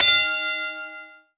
taunt_sfx_bell_single.wav